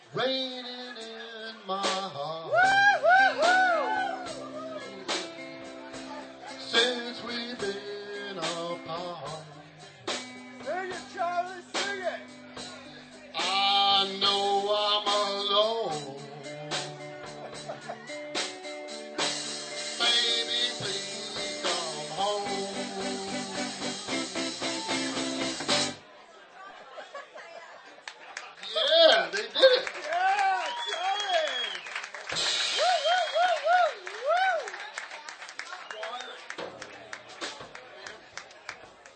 5th OTS Recital - Winter 2005 - rjt_4154